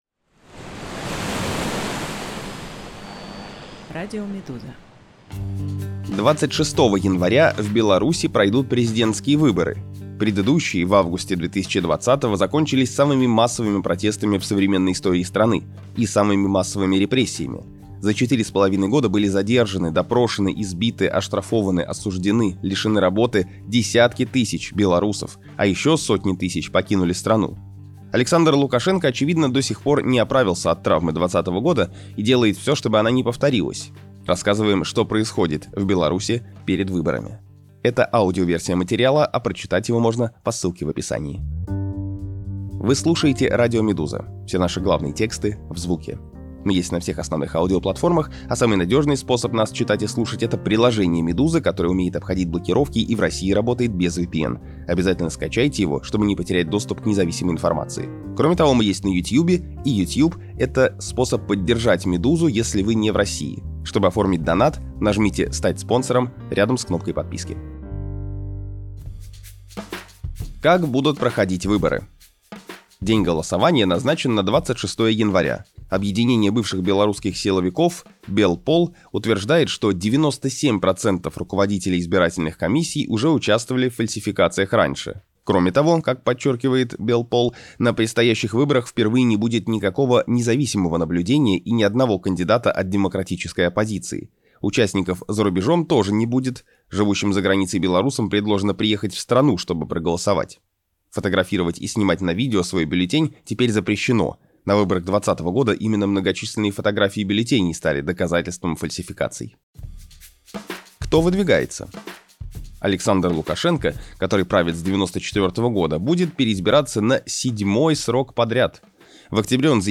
Аудиоверсии главных текстов «Медузы». Расследования, репортажи, разборы и другие материалы — теперь и в звуке.